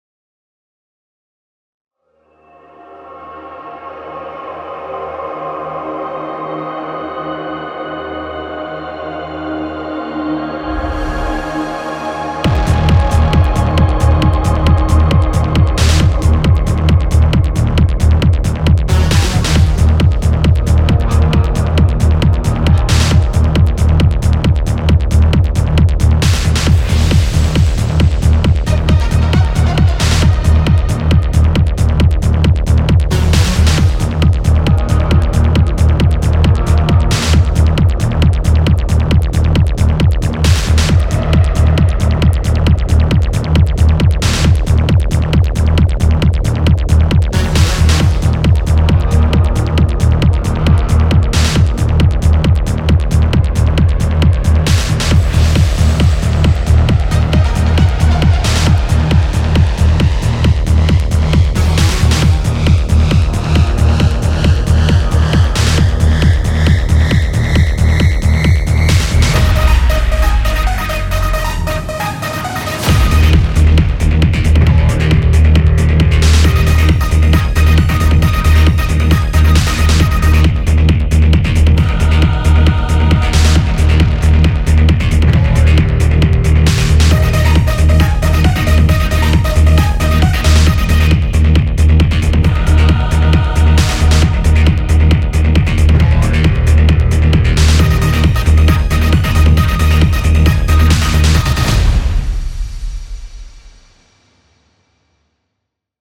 Dark Matter is a premium collection of 100 cinematic EDM bass presets for Reveal Sound’s Spire, designed to inject raw low-end power and futuristic groove into your productions.
From deep, analog-inspired growls to tight, punchy sequences and textured, dystopian tones — this soundset delivers basslines that are both impactful and full of character.
• Powerful and atmospheric low-end sound design
• * The video and audio demos contain presets played from Dark Matter sound bank, every single sound is created from scratch with Spire.
• * All sounds of video and audio demos are from Dark Matter (except drums and additional arrangements).